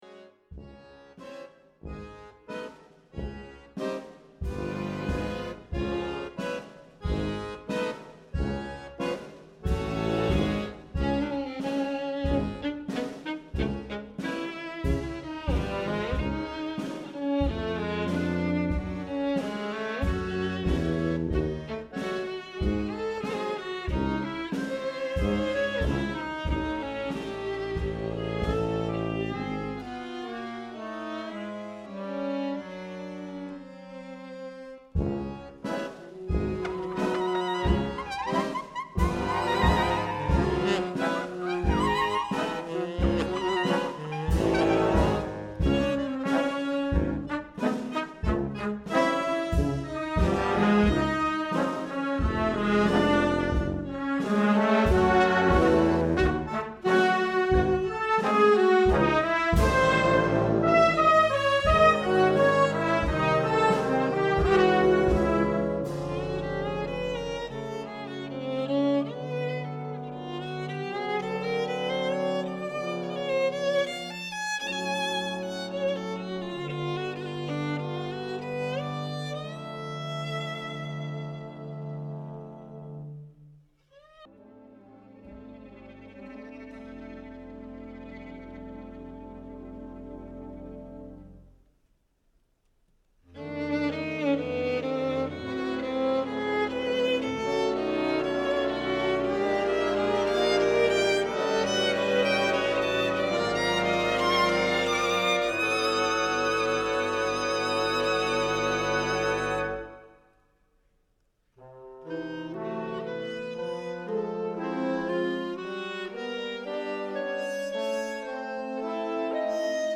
Store/Music/Big Band Charts/ORIGINALS/PORTRAIT
Doubles: clarinet, bass clarinet
Solos: accordion, violin